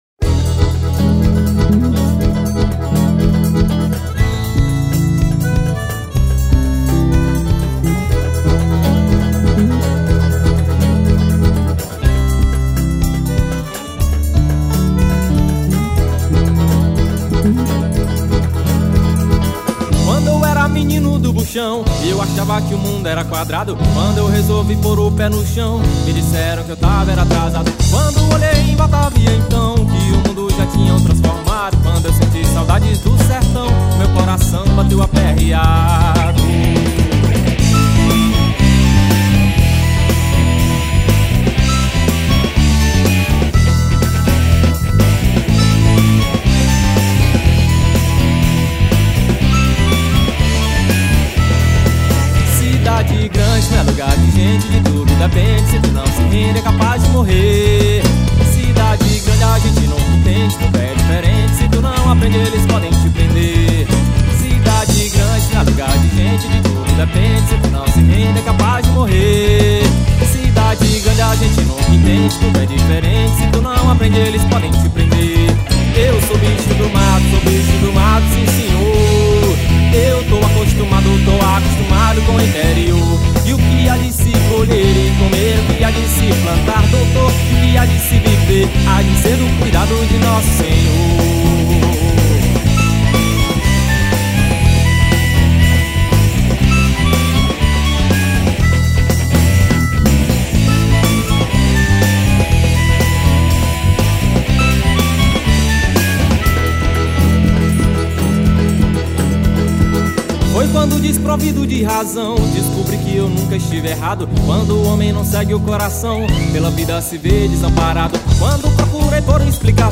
268   04:02:00   Faixa:     Xaxado
Acoordeon
Zabumba
Gaita
Cavaquinho, Pandeiro
Guitarra
Baixo Elétrico 6
Bateria